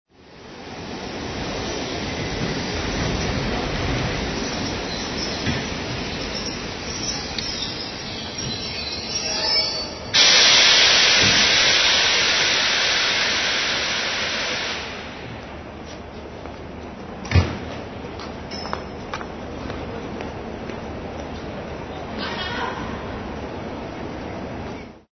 دانلود آهنگ قطار و ایستگاه راه آهن از افکت صوتی حمل و نقل
دانلود صدای قطار و ایستگاه راه آهن از ساعد نیوز با لینک مستقیم و کیفیت بالا
جلوه های صوتی